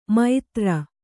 ♪ maitra